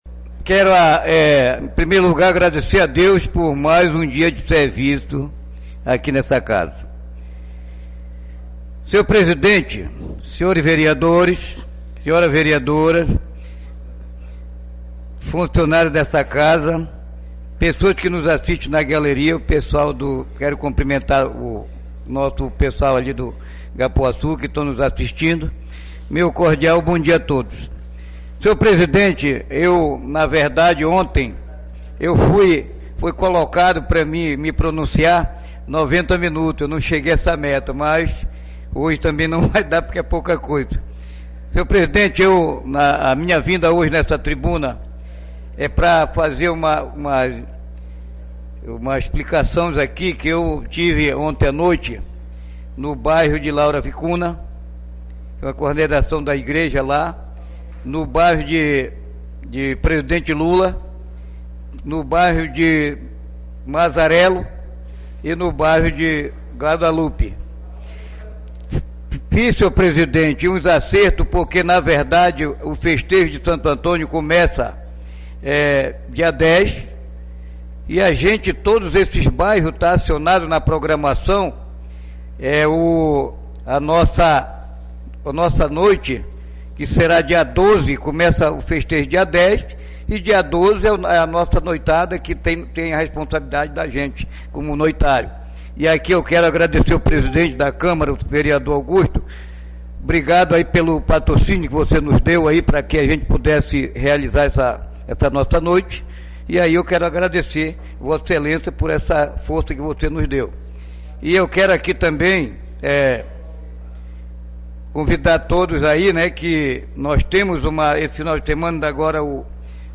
Oradores do Expediente (33ª Sessão Ordinária da 3ª Sessão Legislativa da 31ª Legislatura)
3_fala_mario_do_rosario.mp3